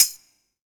BTAMBOURIN2P.wav